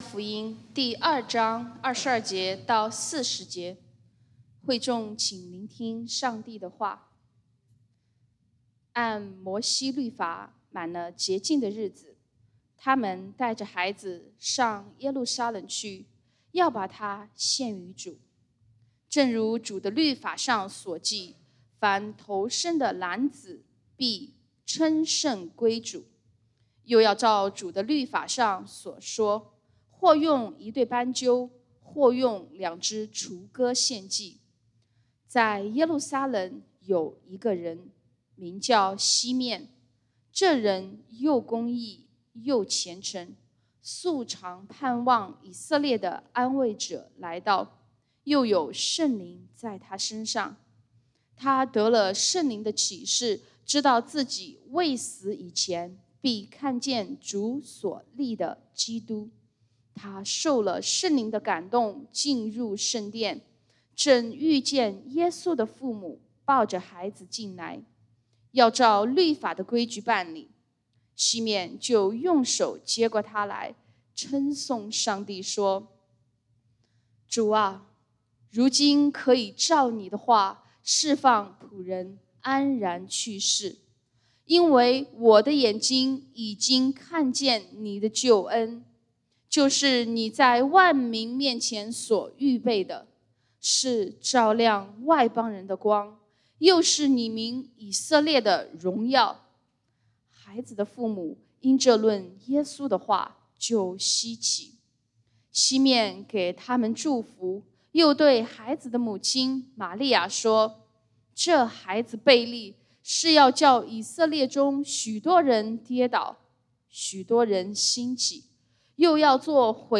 講道經文：《路加福音》Luke 2:22-40 本週箴言：《詩篇》Psalms 62:1-2 (聖經新譯本嚮導版) 「惟獨等候上帝，我的心才有安息；我的拯救從祂而來。